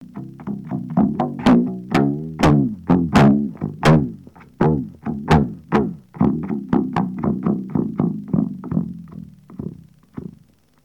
Tin-Can Bass